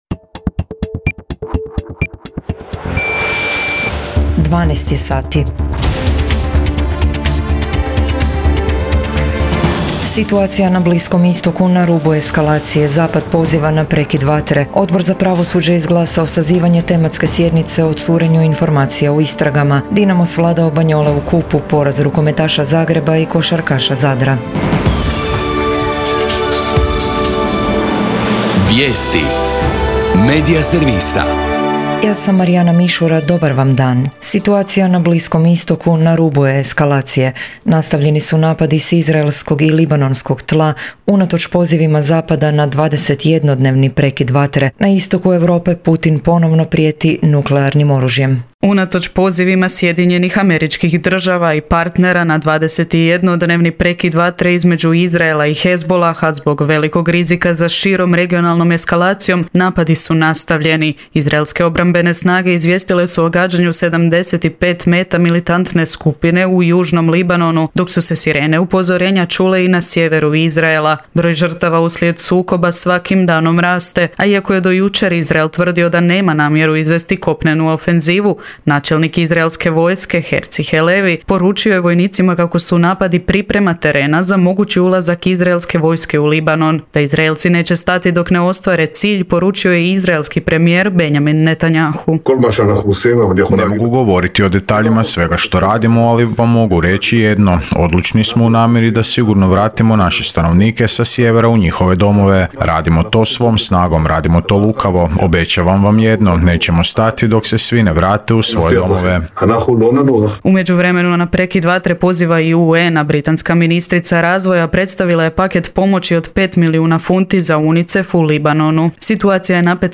VIJESTI U PODNE